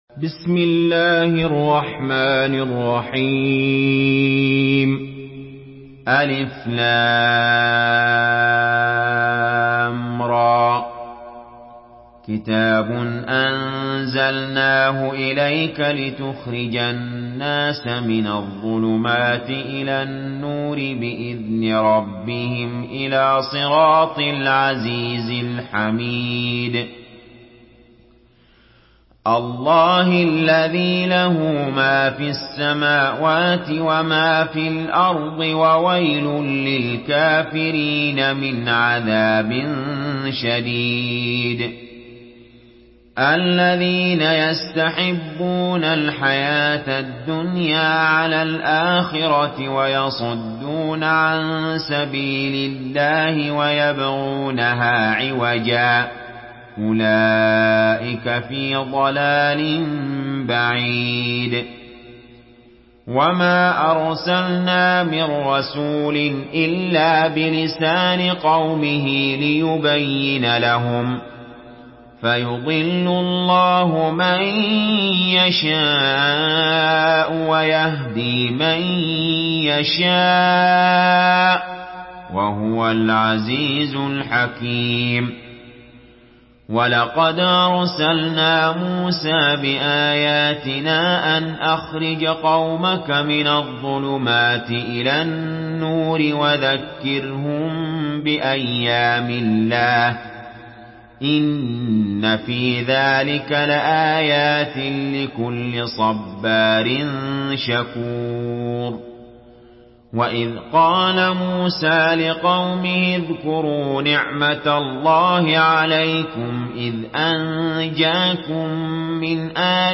Surah Ibrahim MP3 by Ali Jaber in Hafs An Asim narration.
Murattal Hafs An Asim